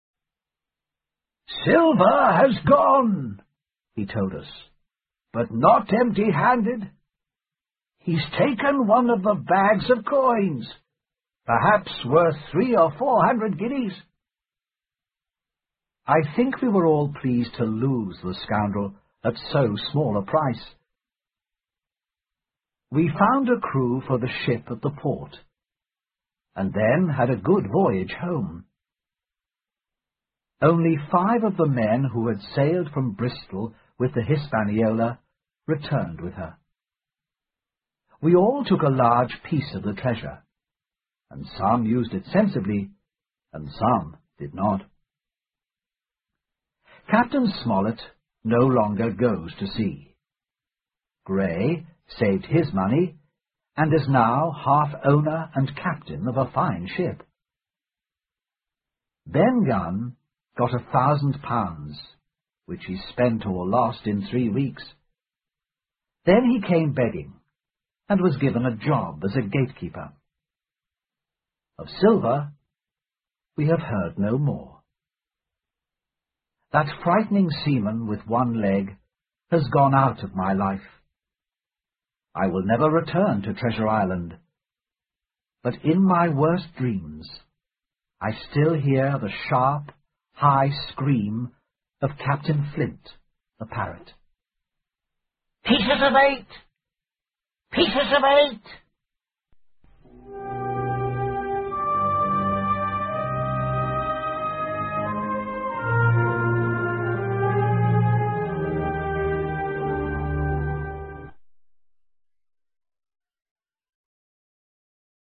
在线英语听力室《金银岛》的听力文件下载,《金银岛》中英双语有声读物附MP3下载